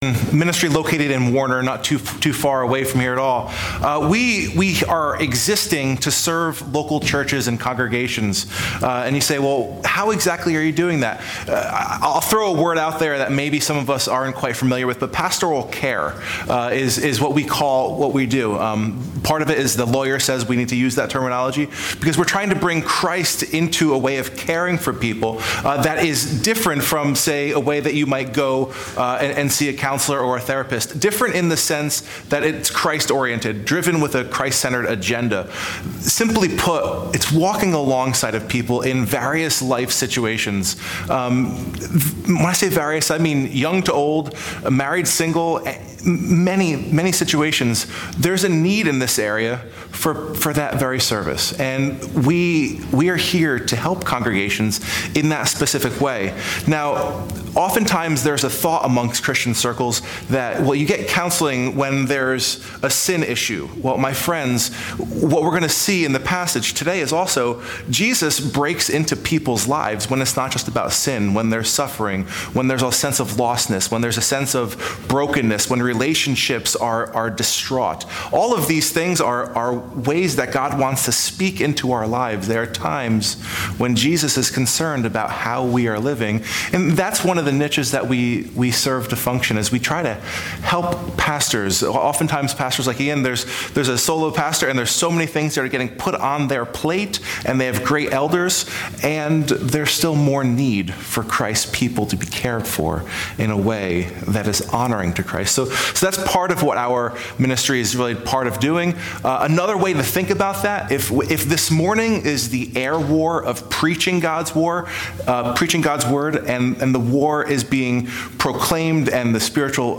“The Unexpected Guest” Guest Preacher